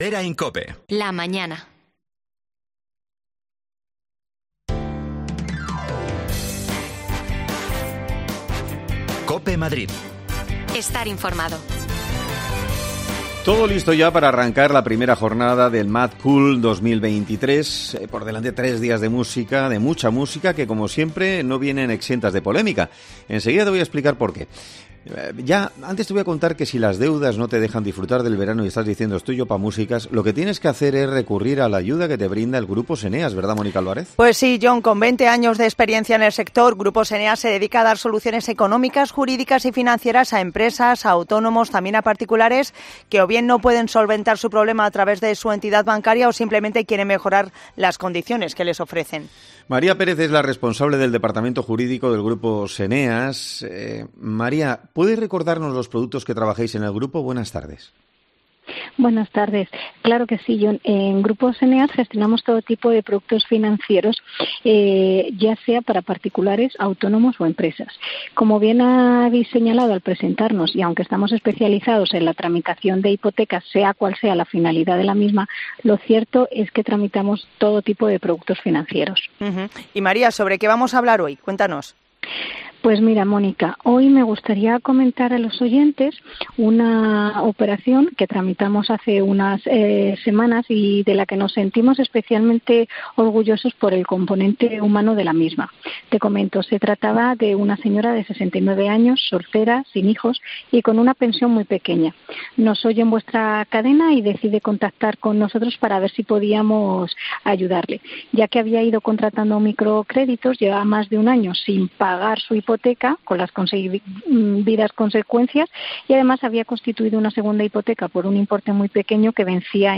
AUDIO: Hoy arranca el MadCool en Villaverde y Getafe al que acudirán 70.000 personas. Te contamos la última hora desde el recinto
Las desconexiones locales de Madrid son espacios de 10 minutos de duración que se emiten en COPE, de lunes a viernes.